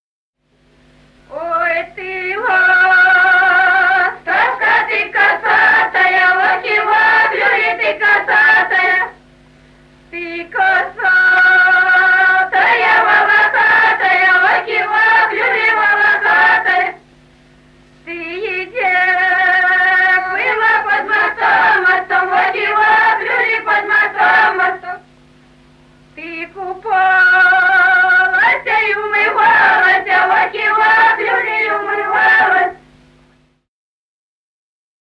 Народные песни Стародубского района «Ой, ты ластовка», юрьевская таночная.
с. Курковичи.